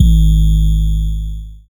DDK1 808 1.wav